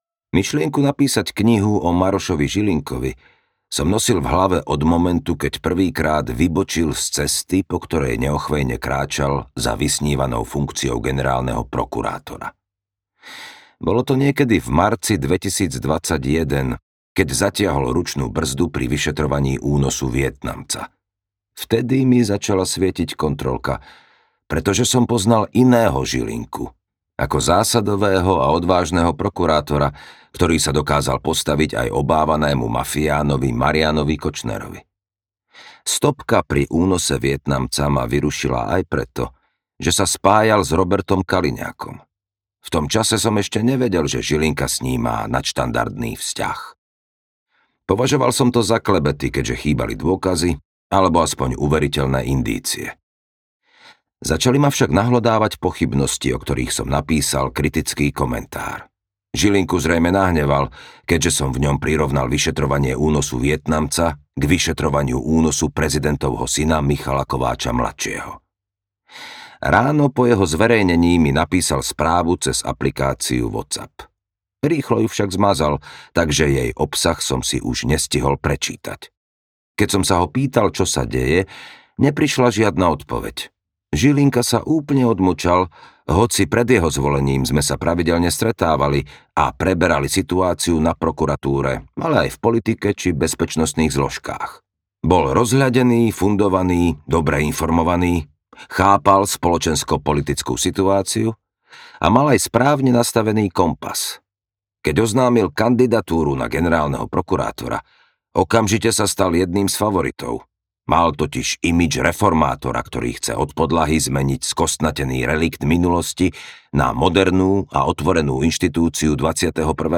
Generálny omyl audiokniha
Ukázka z knihy